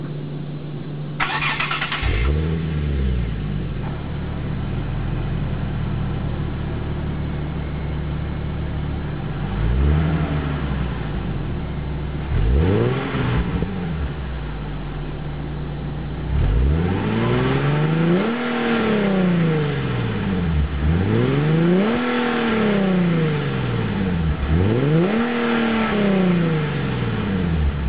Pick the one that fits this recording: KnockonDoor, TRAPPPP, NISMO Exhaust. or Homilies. NISMO Exhaust.